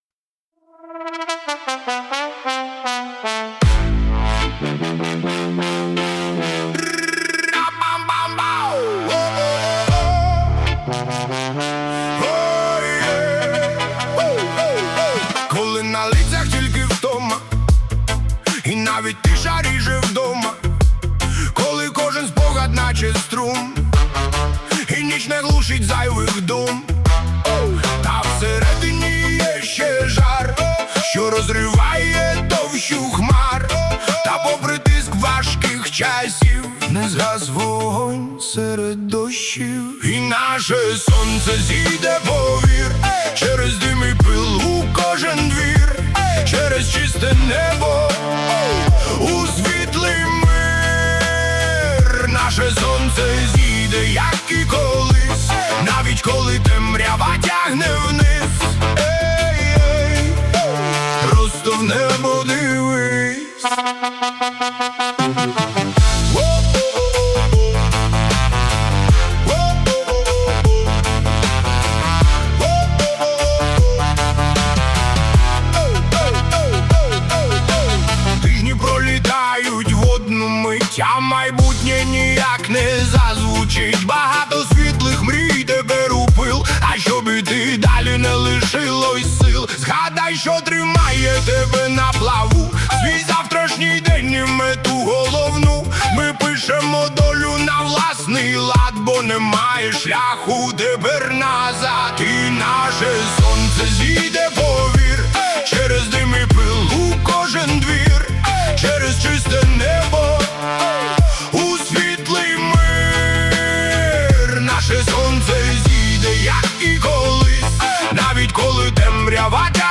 • Жанр: Альтернатива